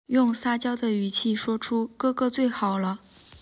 speech generation